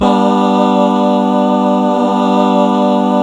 BAAAH   G.wav